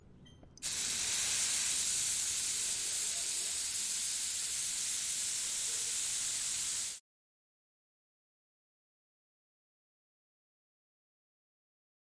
煎蛋
描述：鸡蛋被扔进锅里炒
声道立体声